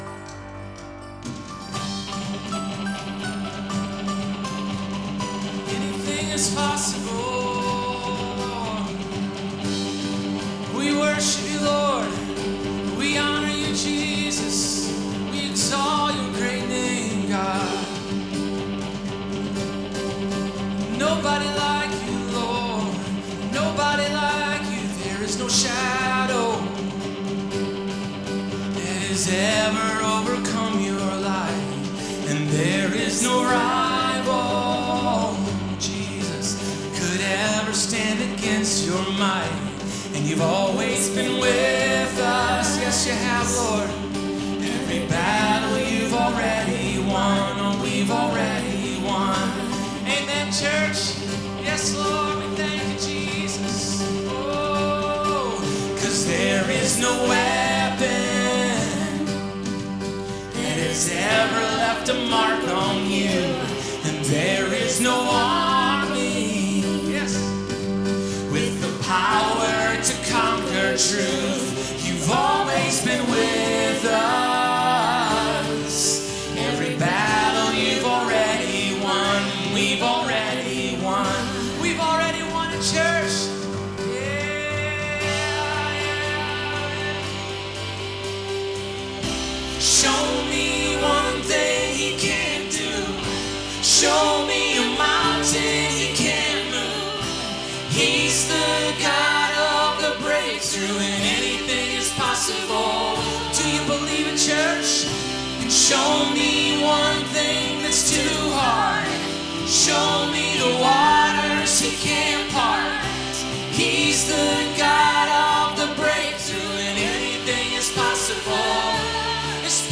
~ Our weekly worship services at Marysville Assembly of God features a diversity of songs from various artists ~